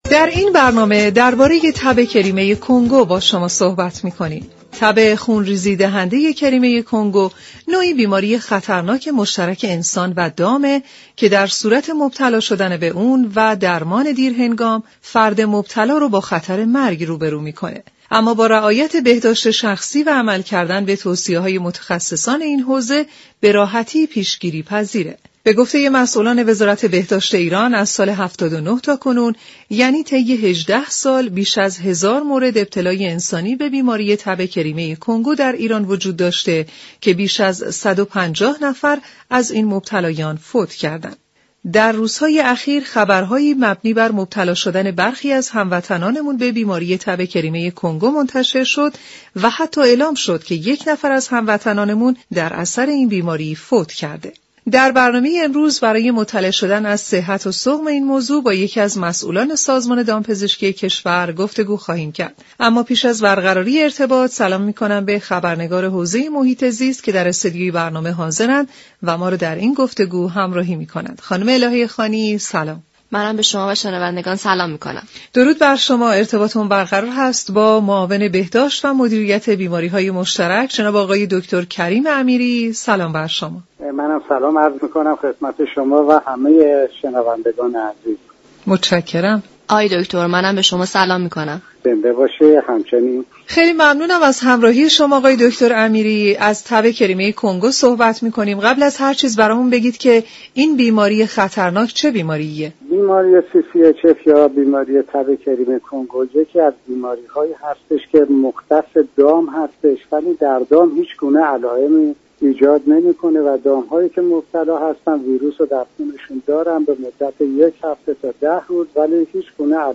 معاون سازمان دامپزشكی؛ در گفت و گو با برنامه «سیاره آبی» گفت